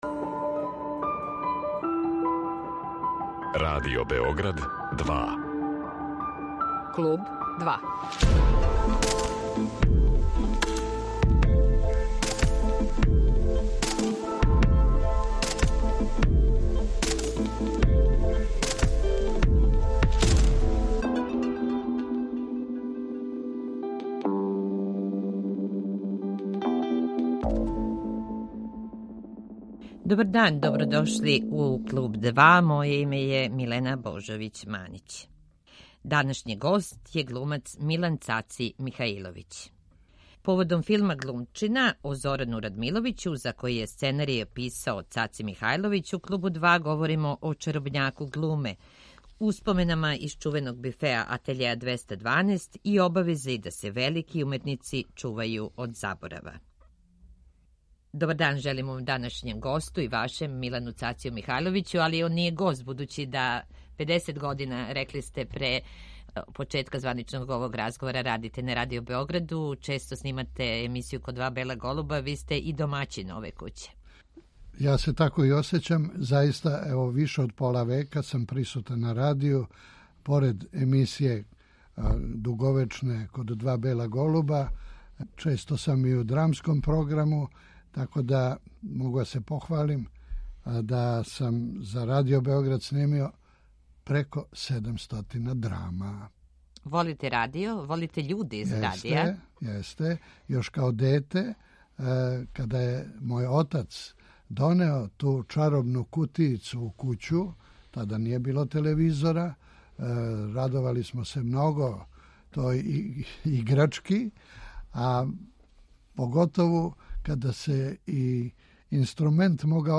Гост Kлуба 2 је глумац Милан Цаци Михаиловић